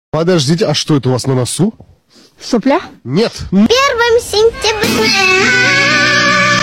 ХААХАХАХАХАХАХ sound effects free download